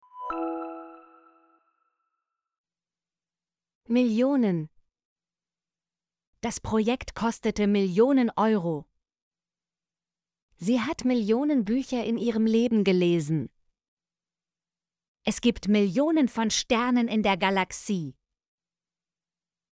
millionen - Example Sentences & Pronunciation, German Frequency List